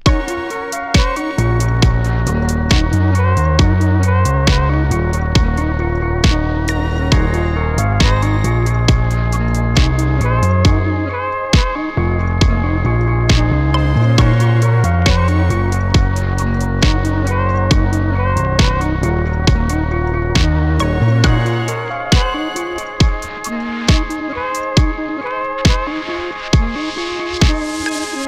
Key Riffer
Electric Muff
Ping Long
Tight Snap
Bb Minor